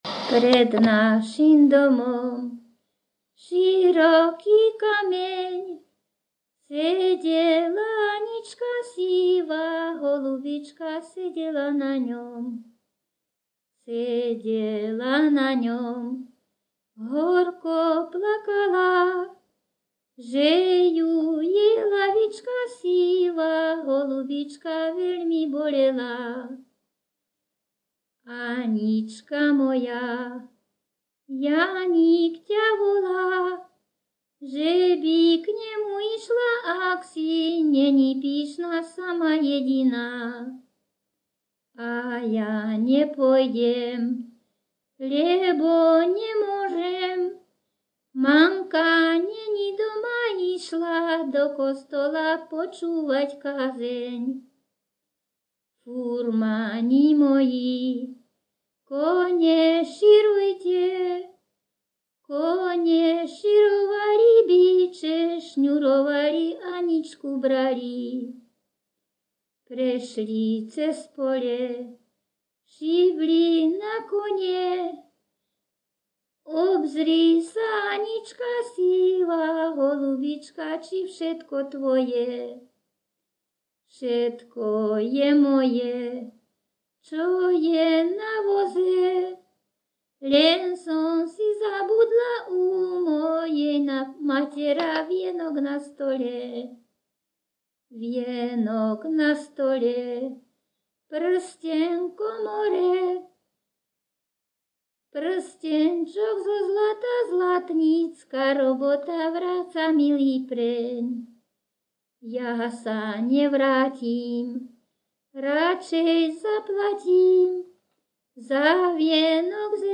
Popis sólo ženský spev bez hudobného sprievodu
Miesto záznamu Litava
Kľúčové slová ľudová pieseň
svadobné piesne